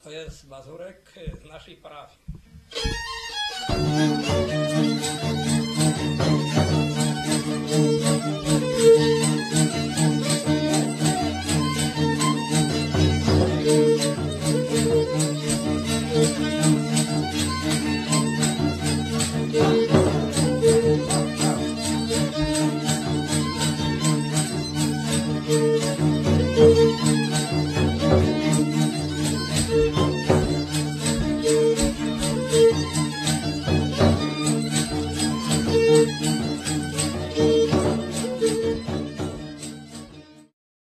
W tle słychać było głosy, jęki, nawoływania.
skrzypce
basy 3-strunowe
bębenek